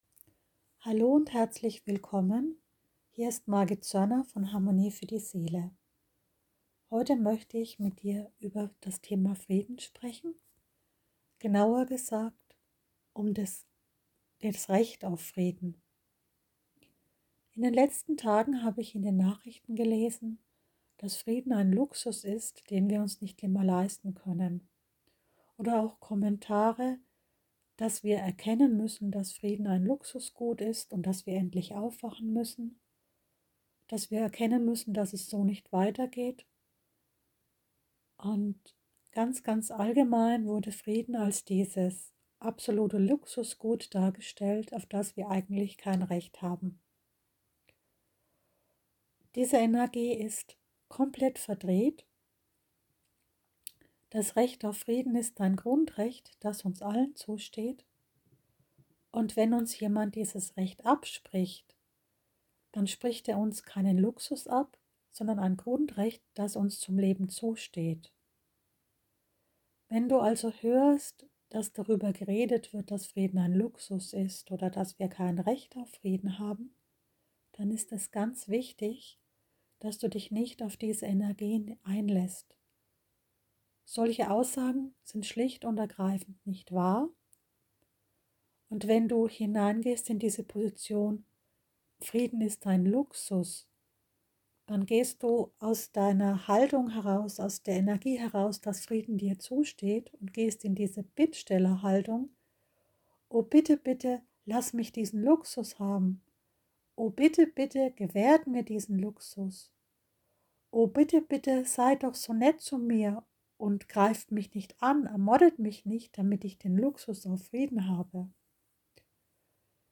Live Aufnahme mit geführter Energieheilung
✨ Die Aufnahme ist eine live Aufnahme ohne technische Überarbeitung. Die Tonqualität ist nicht so hoch, doch die Energie ist super ⇓